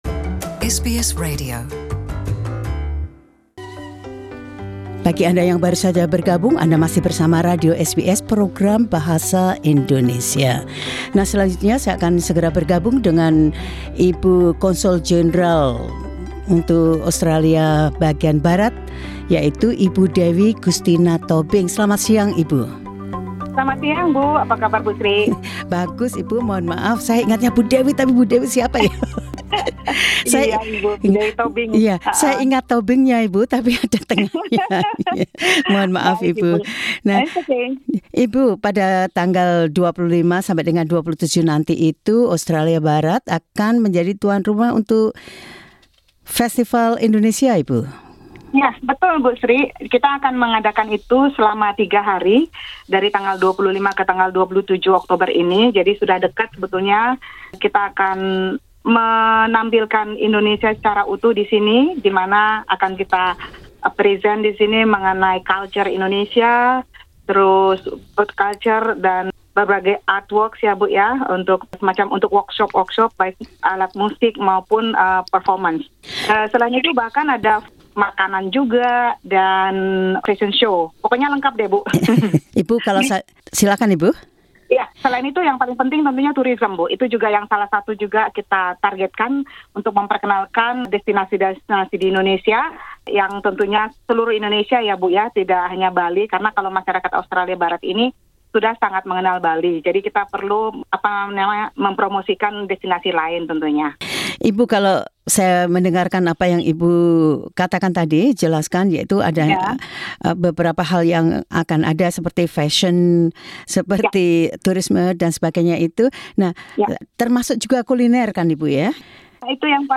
Konsul Jenderal Indonesia untuk Australia Barat Ibu Dewi Gustina Tobing, berbicara tentang tujuan Festival Indonesia dan berbagai kegiatan serta atraksi yang dapat dialami orang ketika mereka mengunjungi Festival Indonesia di Perth akhir bulan ini.